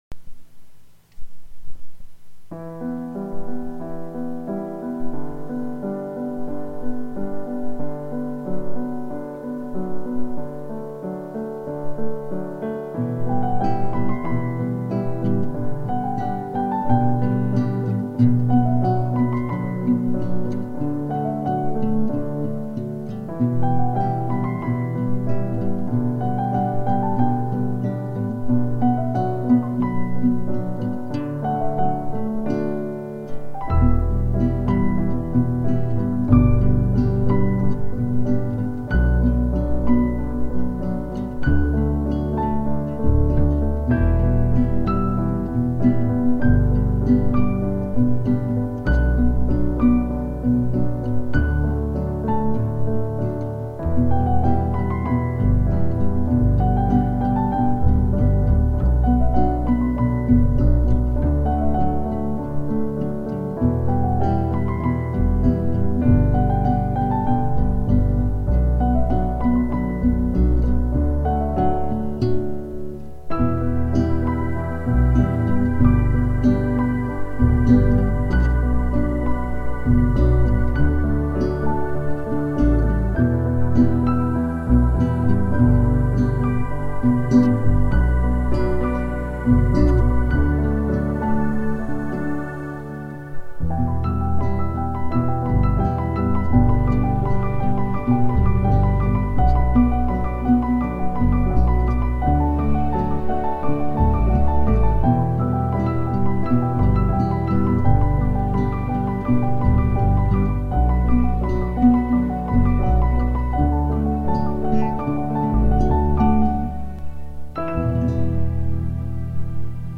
הכלים בהקלטה: פסנתר,בס,Choir,כינורות וגיטרה (הגיטרה אמיתית-ולא עם האורגית.)
הגיטרה מנגנת כאן מינימום, אבל זה בסדר.
יפה! עדין וחלומי:)
וסורי אבלבקושי שומעים תגיטרה...
נשמע כמו איזה פסקול מוכר לסרט..